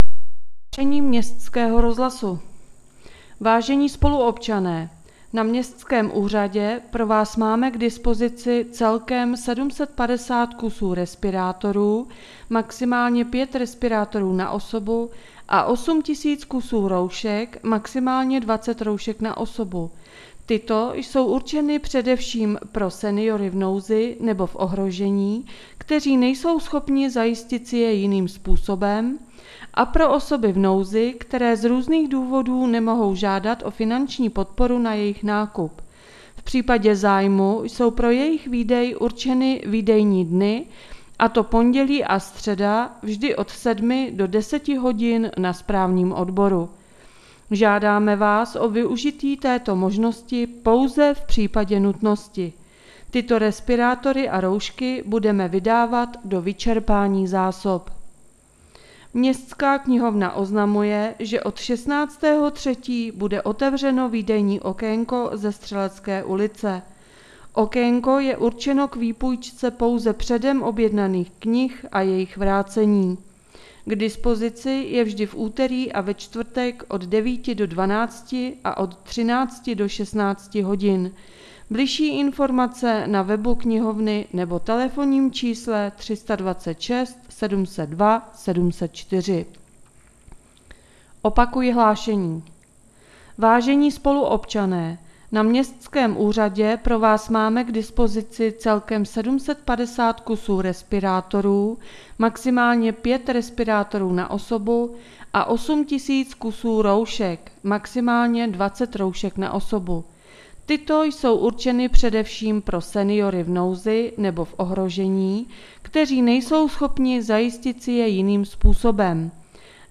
Rozhlas | 96. stránka | Město Bělá pod Bezdězem